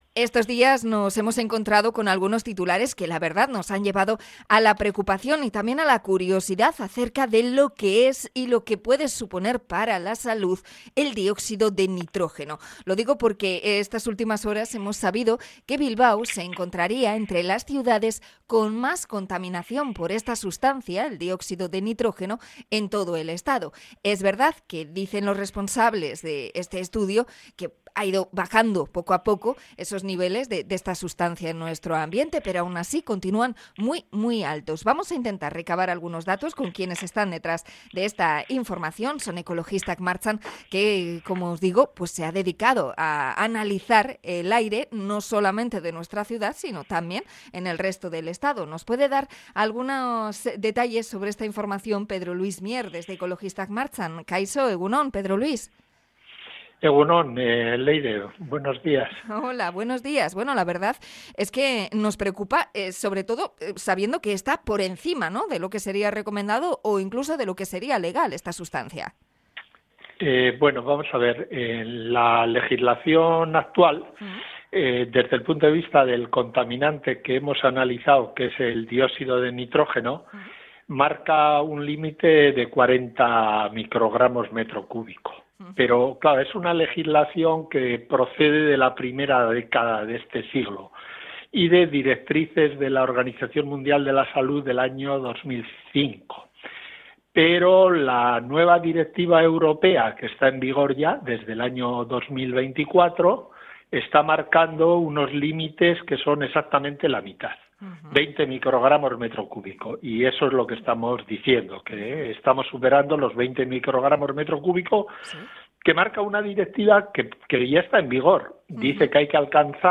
Entrevista a Ekologistak Martxan por el dióxido de nitrógeno